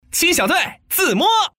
Index of /mahjong_paohuzi_Common_test/update/1658/res/sfx/putonghua/man/